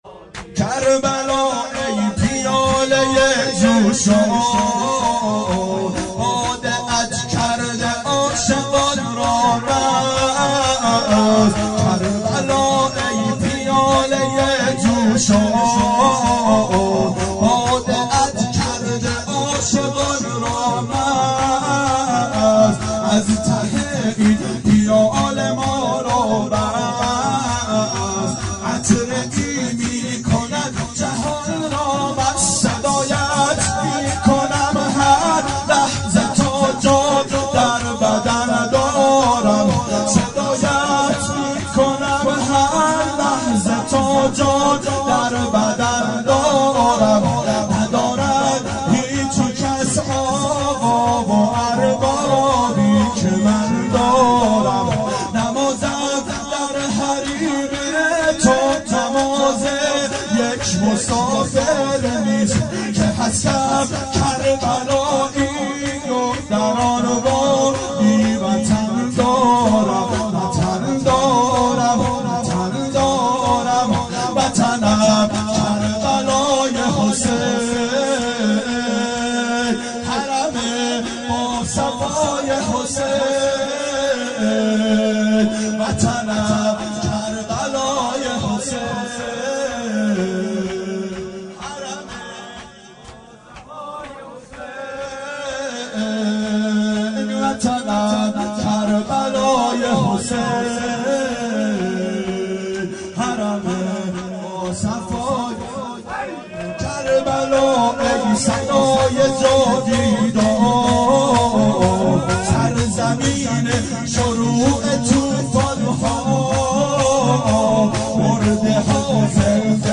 • شب شهادت امام هادی علیه السلام 92 محفل شیفتگان حضرت رقیه سلام الله علیها